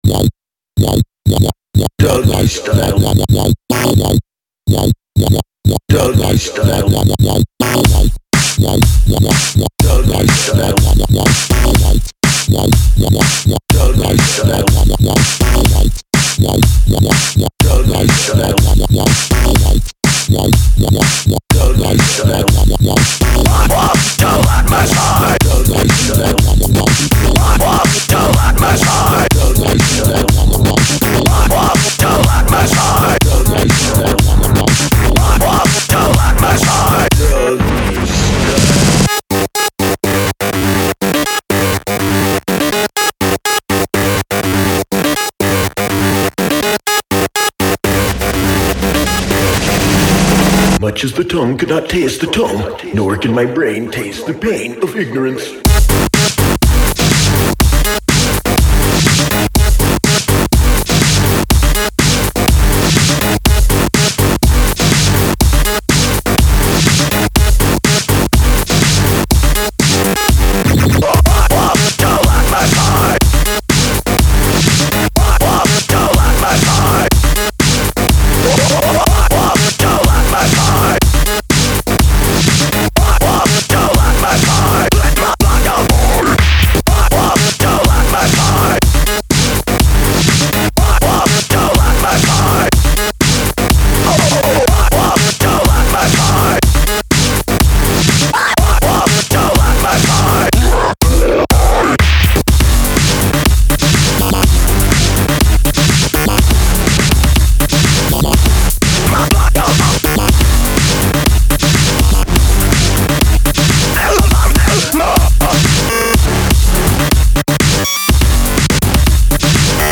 DUB STEP--> [6]
drum step